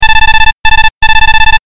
文字送りc長.mp3